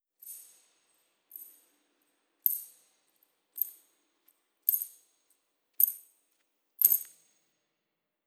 Danza árabe, bailarina se acerca con una tobillera de monedas
agitar
Sonidos: Acciones humanas